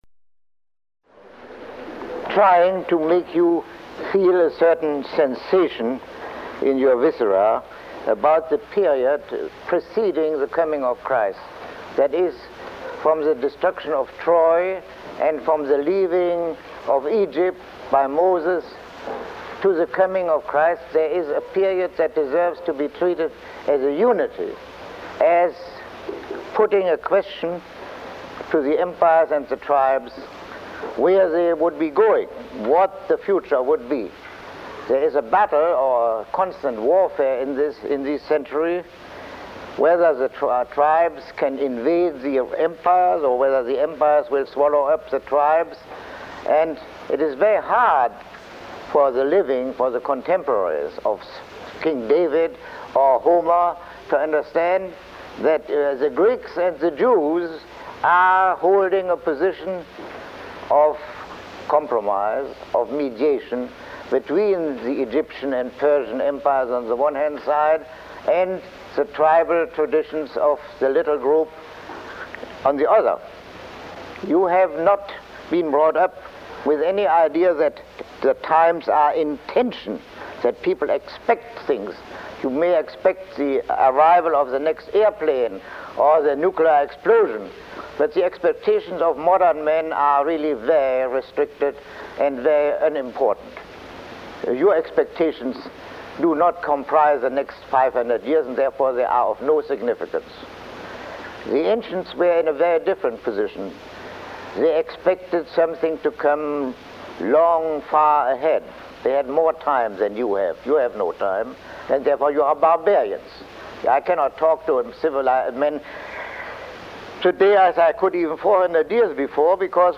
Lecture 14